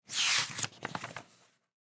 Book Page (3).wav